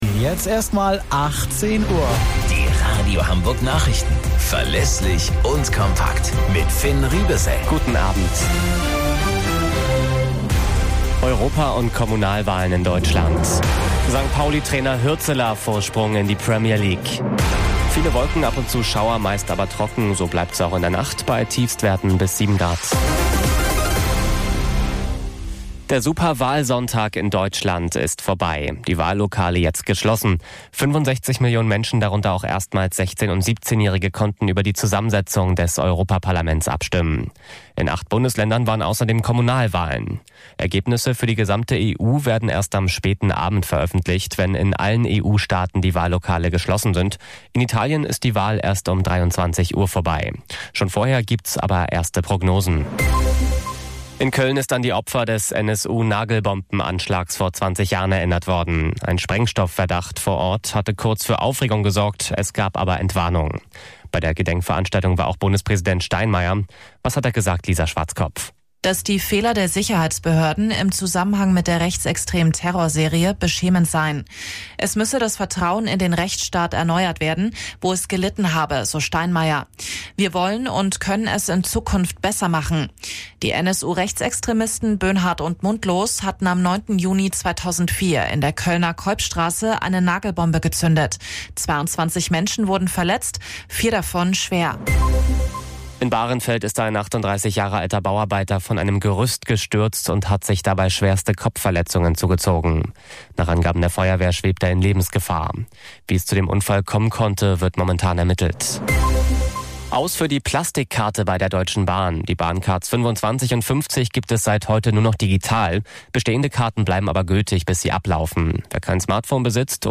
Radio Hamburg Nachrichten vom 09.06.2024 um 18 Uhr - 09.06.2024